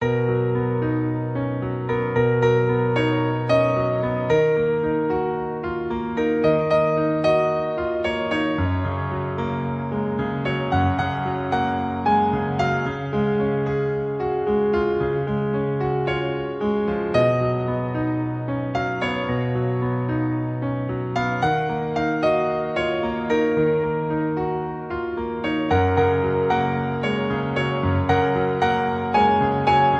• Key: F# Major
• Instruments: Piano solo
• Genre: Pop, Film/TV